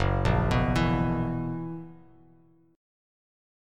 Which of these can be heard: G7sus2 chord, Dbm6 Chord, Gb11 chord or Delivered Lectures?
Gb11 chord